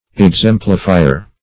Exemplifier \Ex*em"pli*fi`er\, n. One who exemplifies by following a pattern.